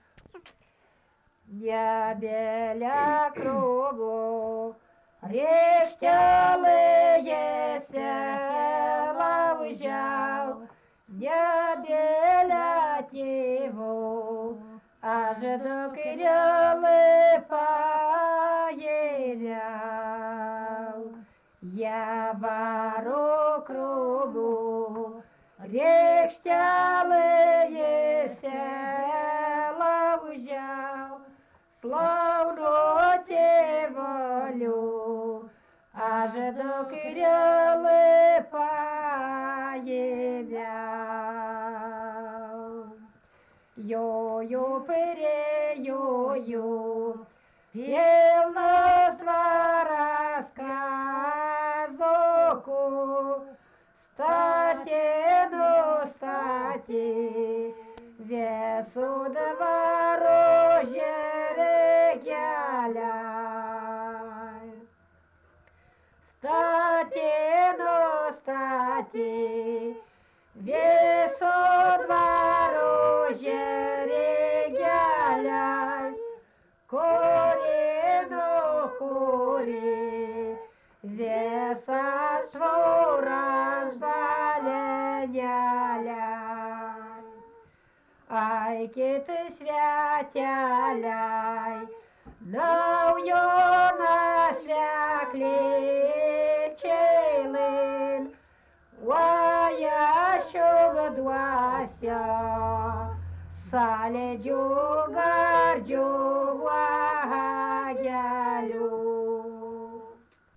daina
vestuvių